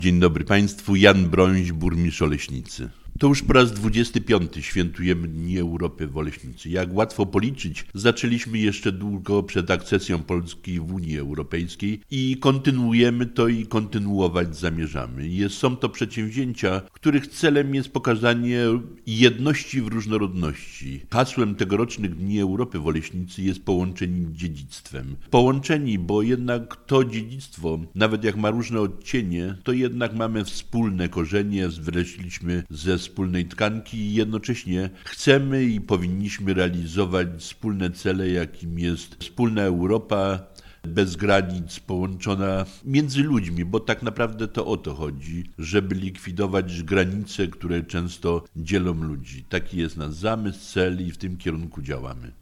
-Są to przedsięwzięcia, których celem jest pokazanie jedności w różnorodności. Hasłem tegorocznych Dni Europy w Oleśnicy jest „Połączeni dziedzictwem”. – mówi Burmistrz Oleśnicy, Jan Bronś.